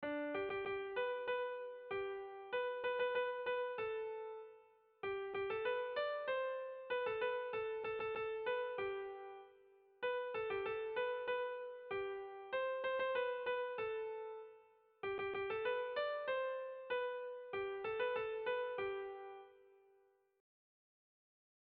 Erlijiozkoa
Ezkurra < Malerreka < Iruñeko Merindadea < Nafarroa < Euskal Herria
Zortziko txikia (hg) / Lau puntuko txikia (ip)
ABDB